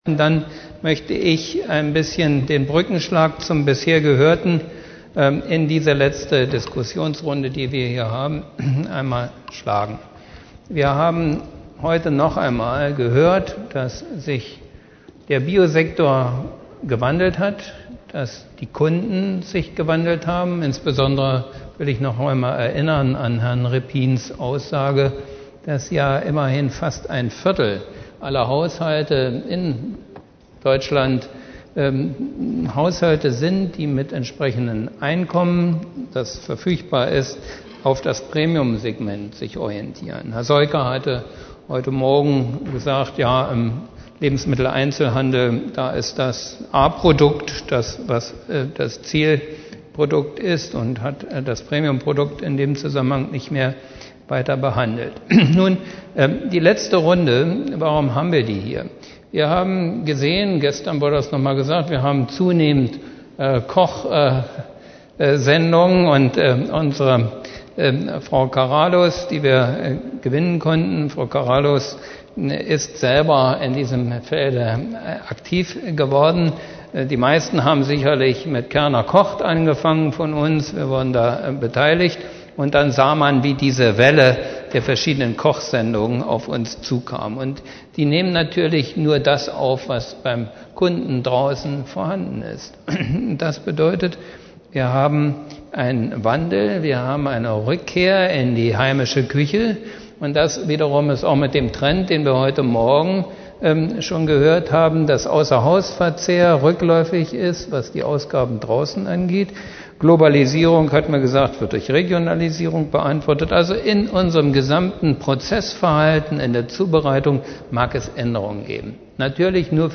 3bhf_2008_09_17_9_Diskussion_Genuss.mp3